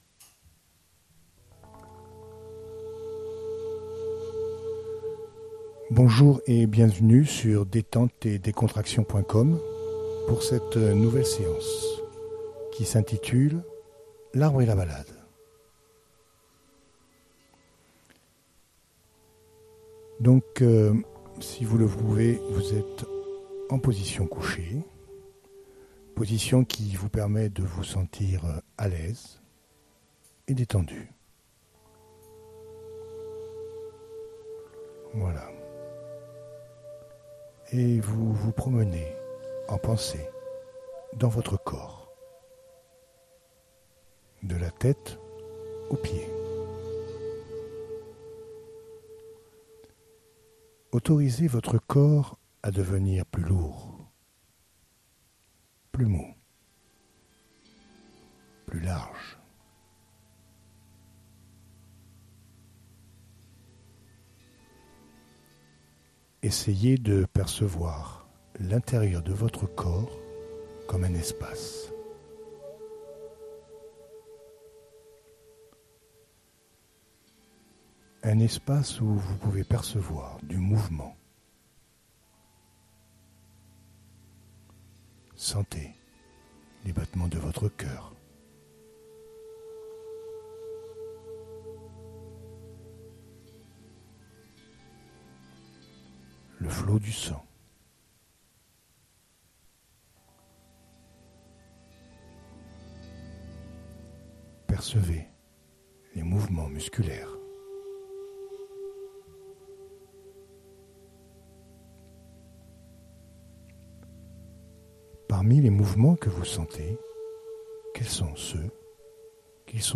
La relaxation guidée